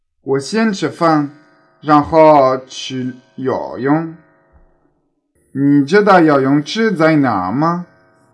口音有声数据
口音（男声）